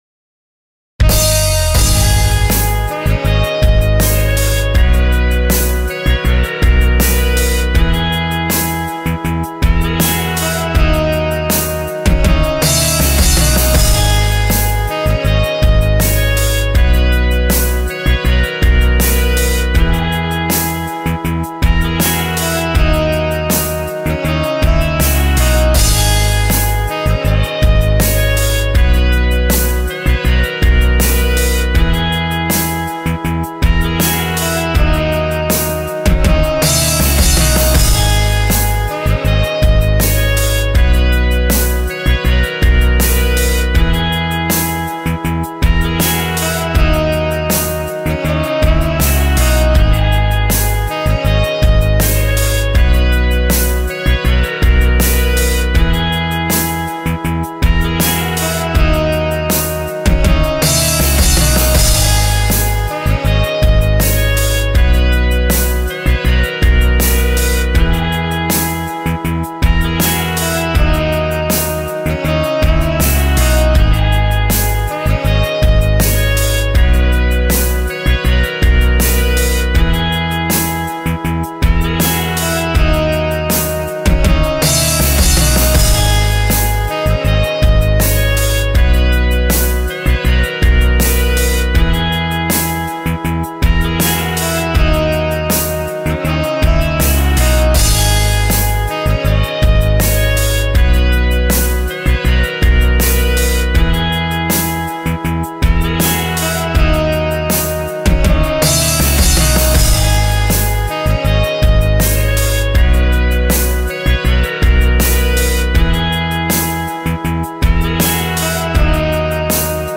saxophone
smooth jazz, instrumental tune